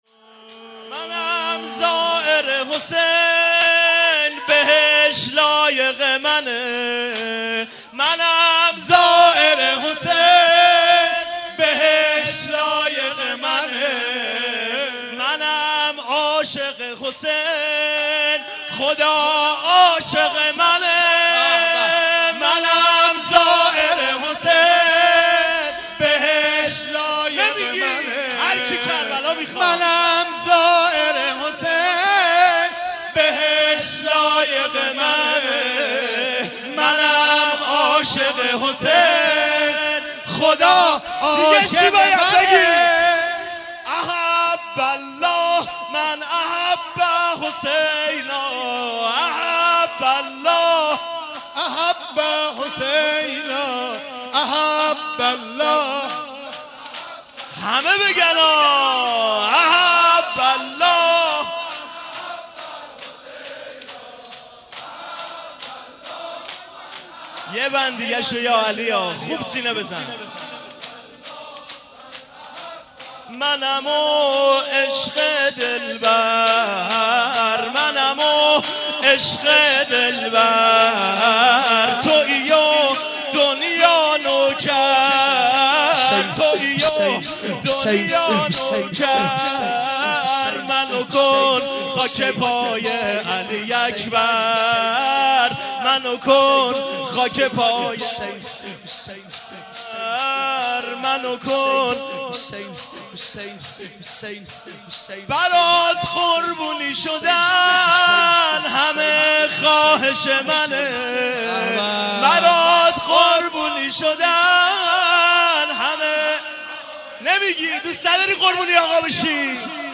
مناسبت : شب بیست و دوم رمضان
قالب : زمینه